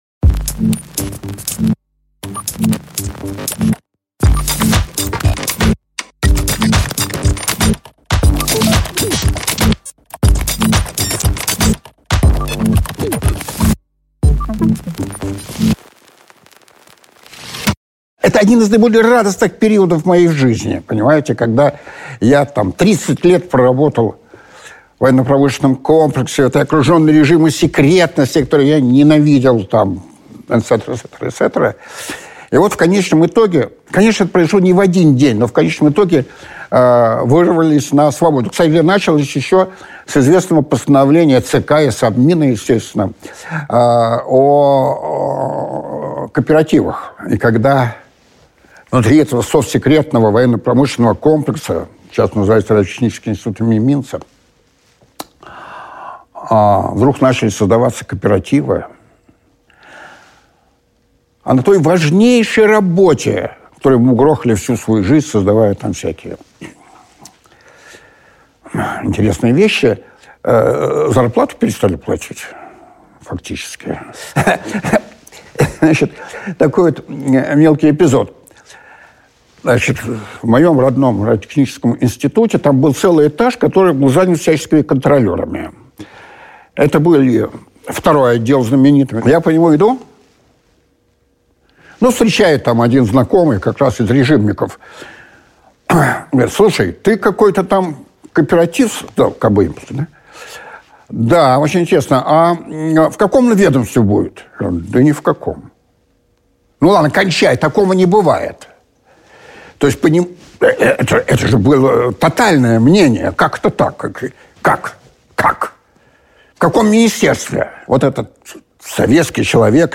Аудиокнига Зарождение капитализма в России – свидетельства участника | Библиотека аудиокниг